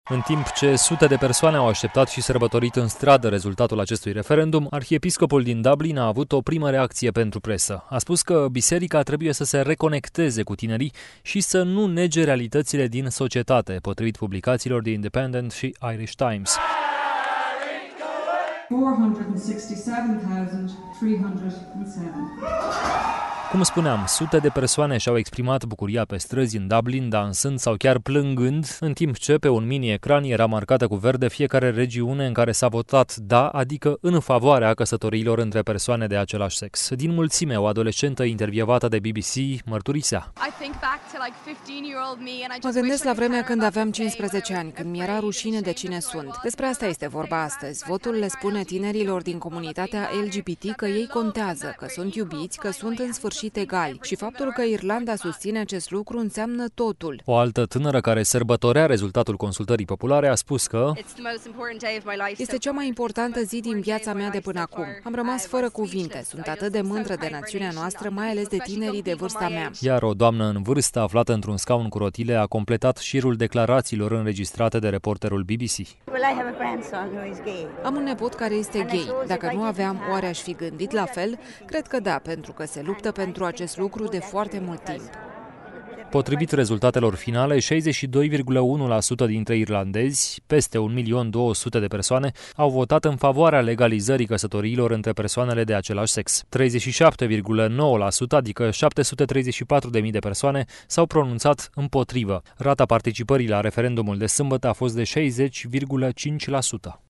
Sute de persoane și-au exprimat bucuria pe străzi în Dublin – dansând sau chiar plângând – în timp ce, pe un ecran, era marcată cu verde fiecare regiune în care s-a votat ”DA”, adică în favoarea căsătoriilor între persoane de același sex.
O doamnă în vârstă, aflată într-un scaun cu rotile, a completat șirul declarațiilor înregistrate de reporterul BBC: ” Am un nepot care este gay.